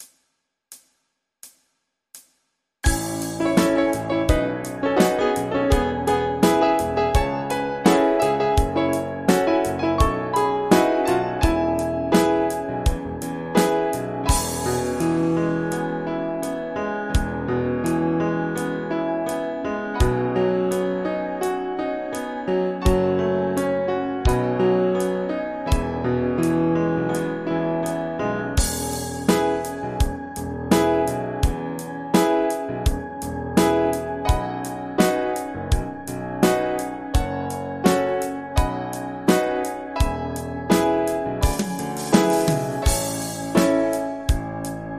MIDI · Karaoke
se escucha muy bien...